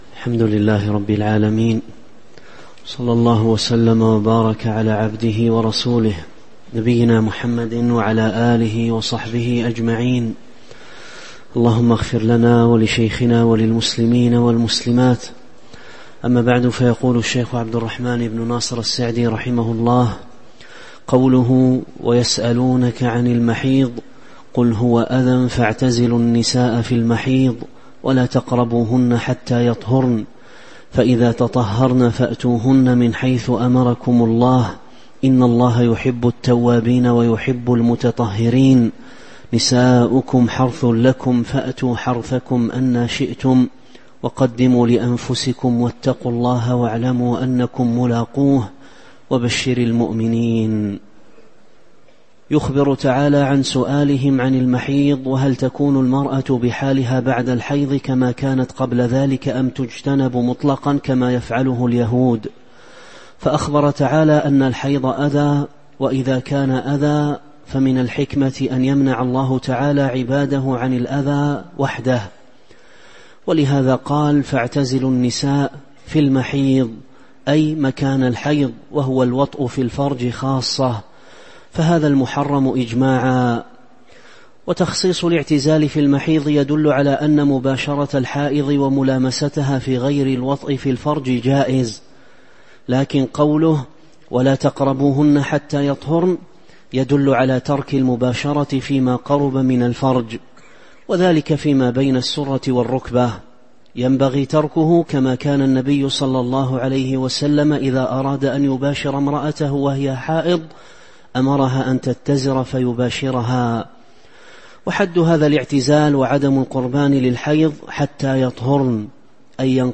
تاريخ النشر ١١ شعبان ١٤٤٦ هـ المكان: المسجد النبوي الشيخ